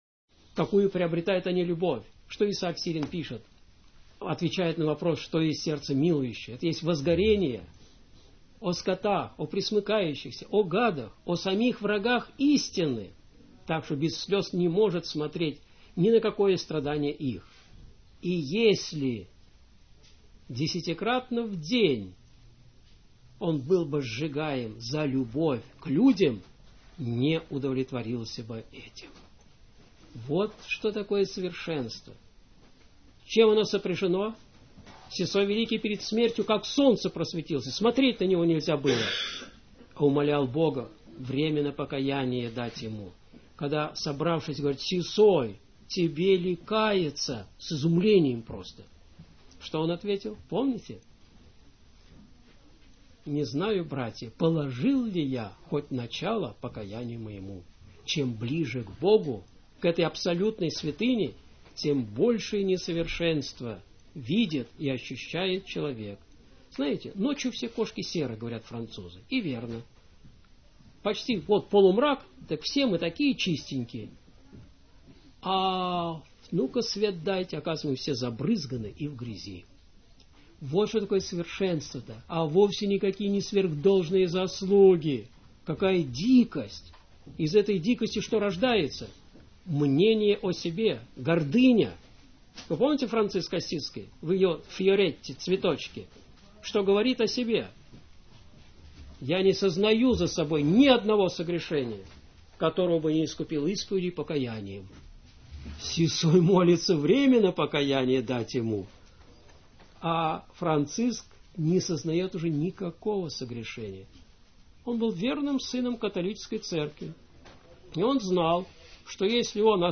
Общественные лекции.